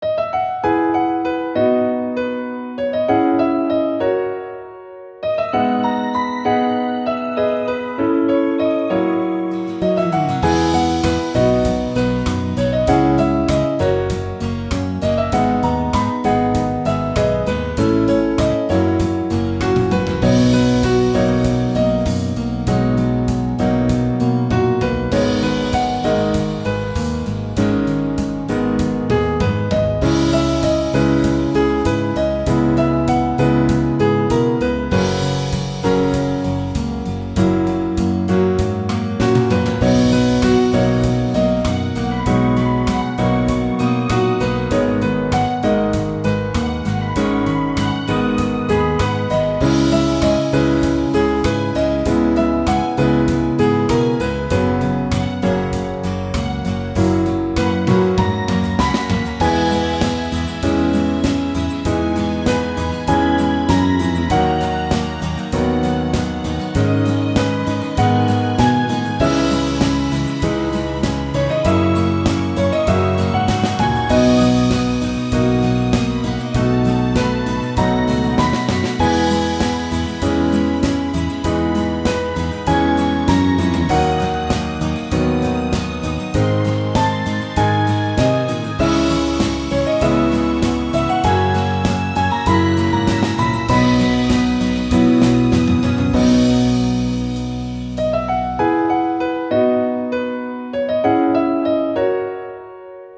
ogg(L) キャラ かわいい ポップ
ゆったりポップの可愛らしい曲。